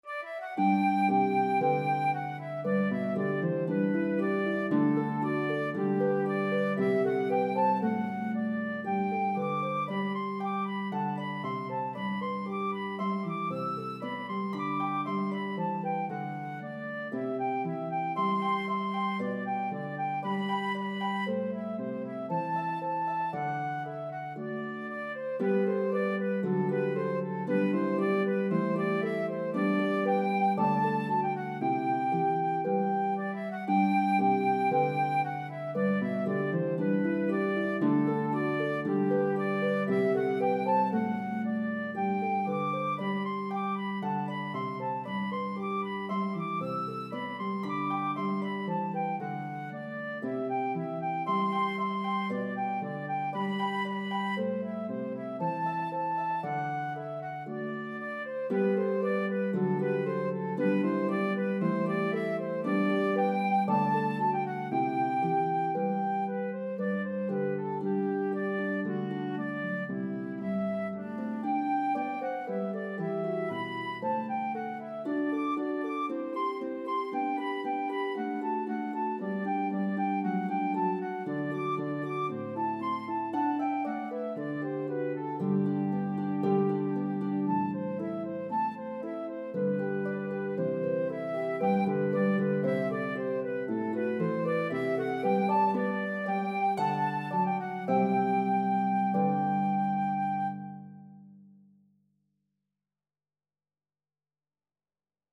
popular Italian style of the day